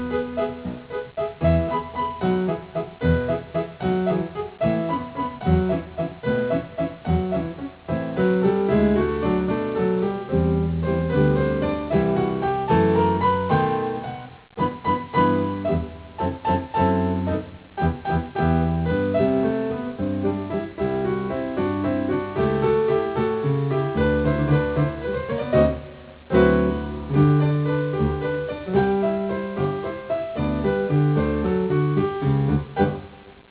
お馴染の曲が、オリジナルの響きを損なわない程度に弾き易い連弾曲として編曲されています。